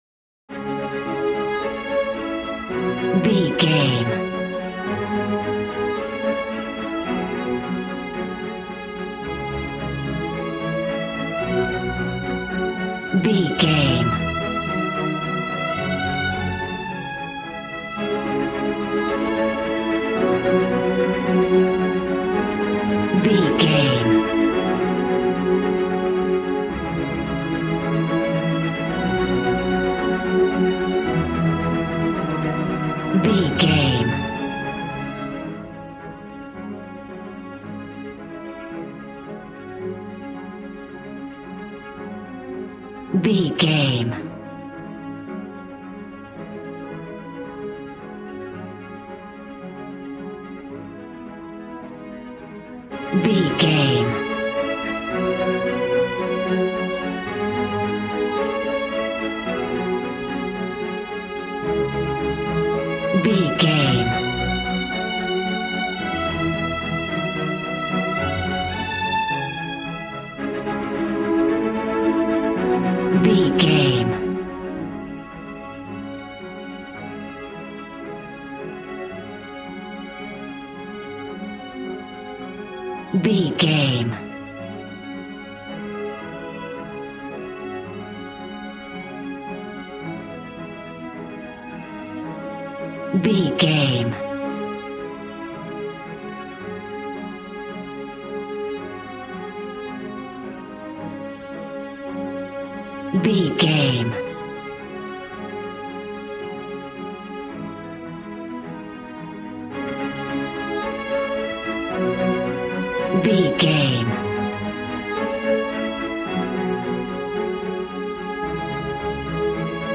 Regal and romantic, a classy piece of classical music.
Aeolian/Minor
D♭
regal
strings
brass